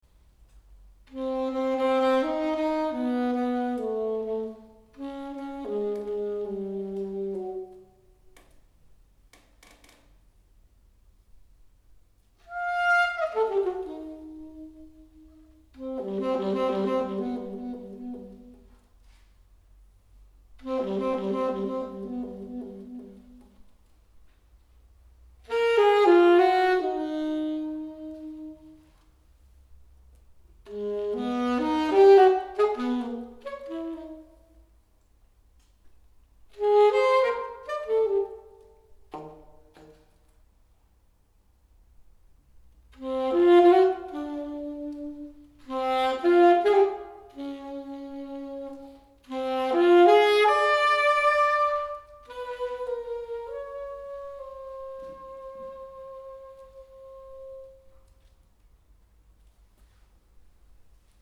solo for optional woodwind